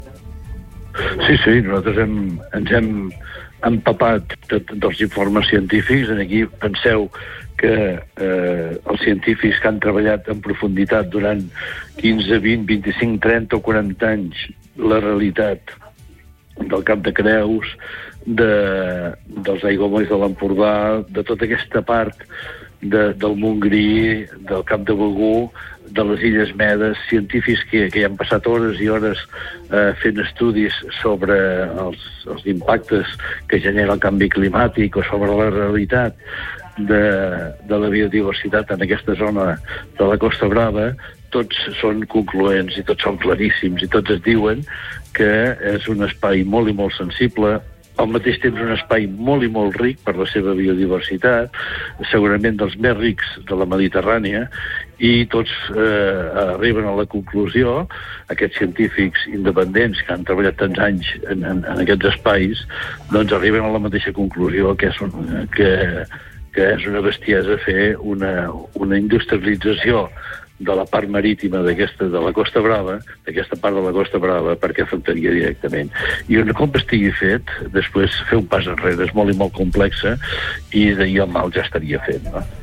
Supermatí - entrevistes
I per parlar d’aquesta moció ens ha visitat al Supermatí l’alcalde de Torroella de Montgrí, Jordi Colomí.